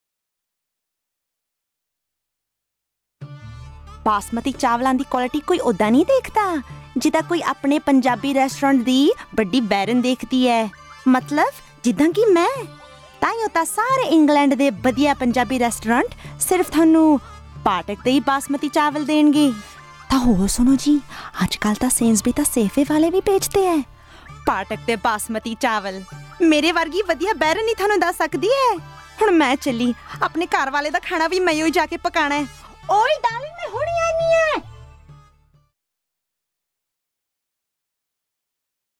40/50s, Midlands/Indian/RP,
Warm/Versatile/Experienced